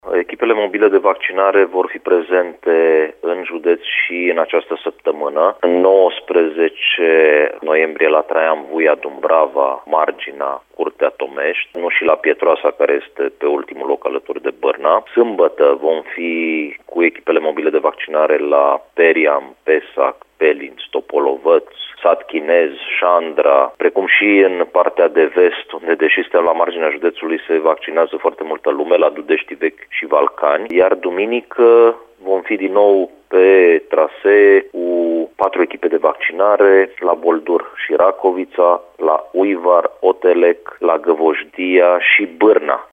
Sunt vizate localități de pe toată raza județului, a precizat la Radio Timișoara subprefectul Ovidiu Drăgănescu.